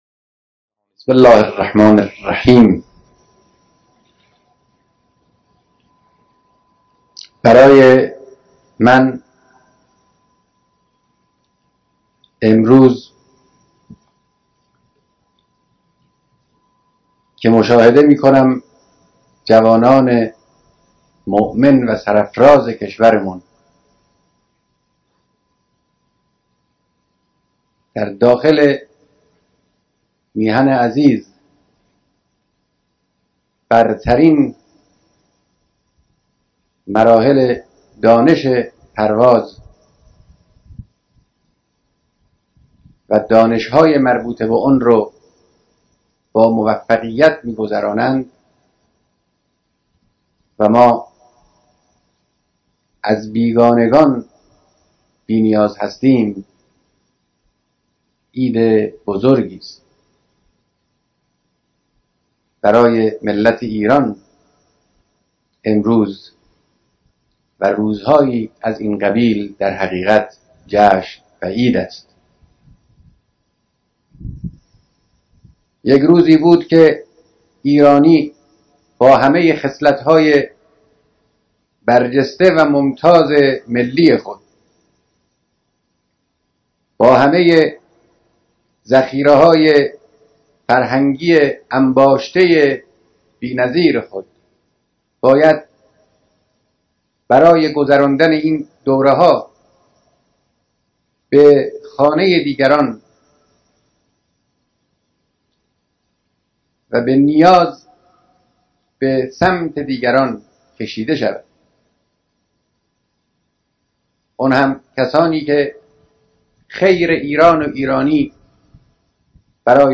بیانات رهبر انقلاب در مراسم فارغ‌التحصیلی و اخذ سردوشی در دانشگاه هوایی